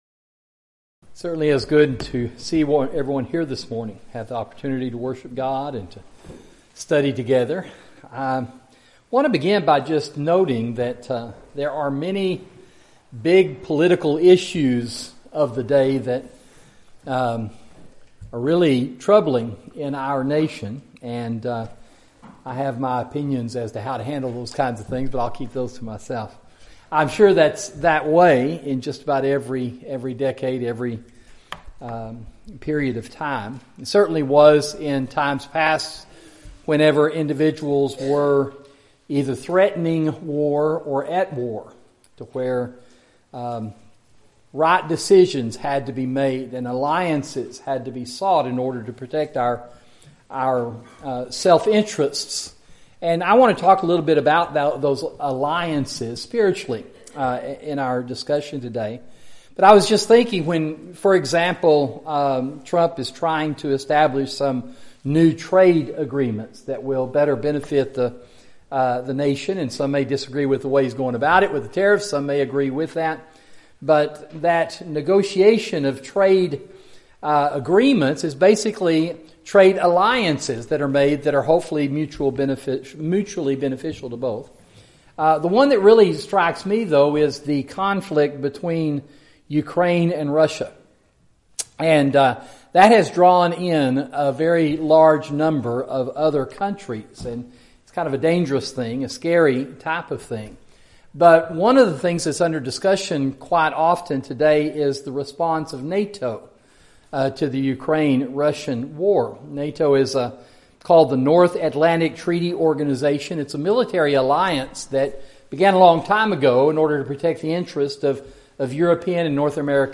Sermon: Proper Alliances